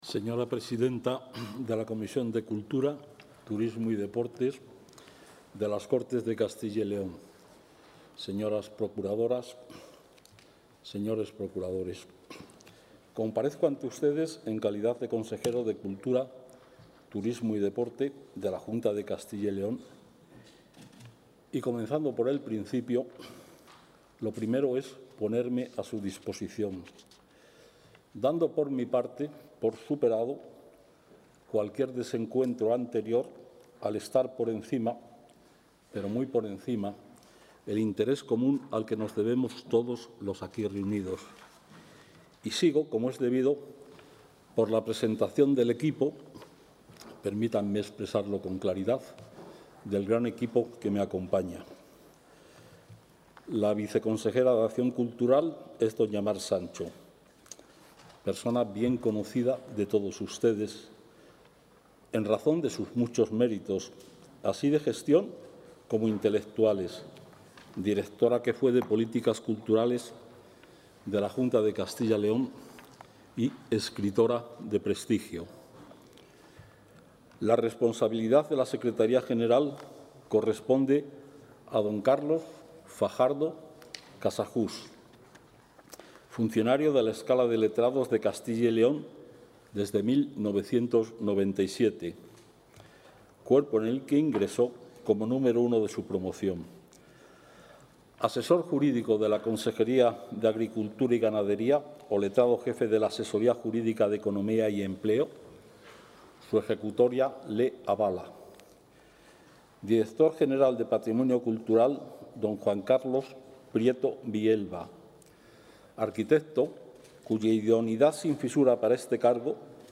Audio consejero.
El consejero de Cultura, Turismo y Deporte, Gonzalo Santonja, ha comparecido hoy en la Comisión de Cultura, Turismo y Deporte de las Cortes de Castilla y León para exponer los puntos de actuación previstos para la XI Legislatura, entre los que ha destacado el castellano, nuestra lengua universal, la investigación sobre sus orígenes y el legado histórico de los fueros, que marca el comienzo del municipalismo y las libertades en plena Edad Media.